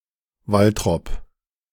Waltrop (German pronunciation: [ˈvaltʁɔp]
De-Waltrop.ogg.mp3